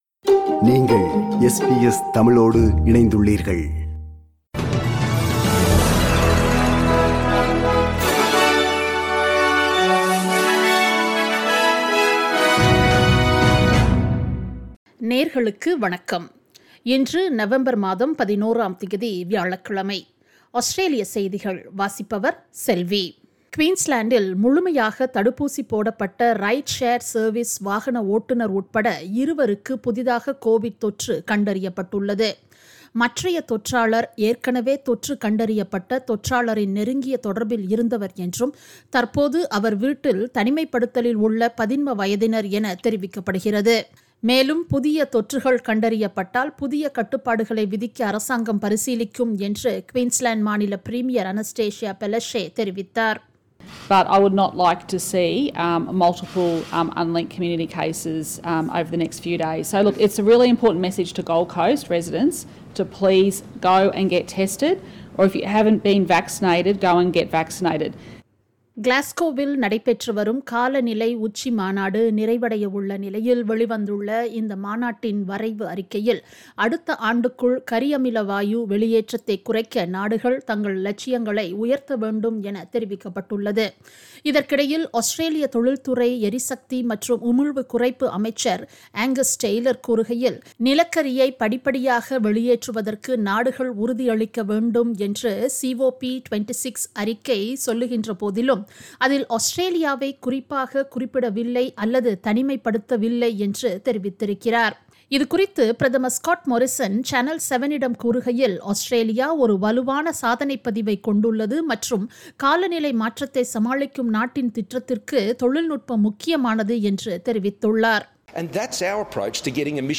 Australian news bulletin for Thursday 11 November 2021.
australian_news_11_nov_-_thursday.mp3